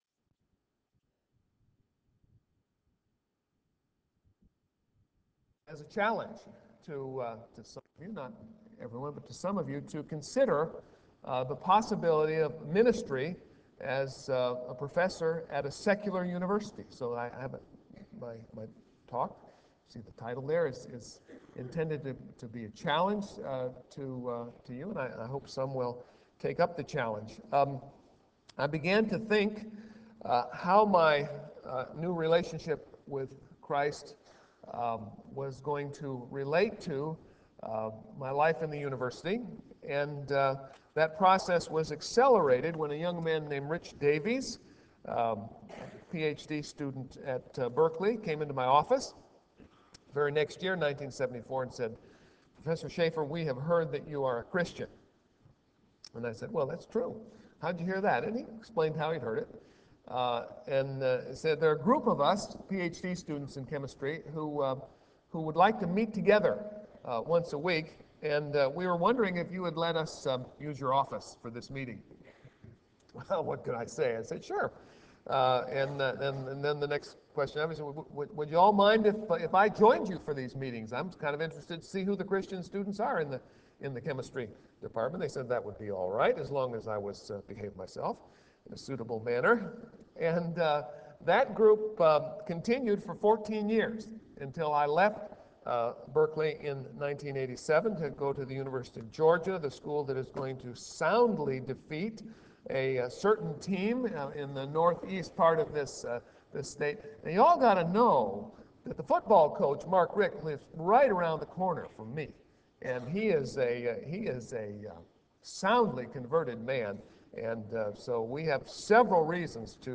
Chapel Service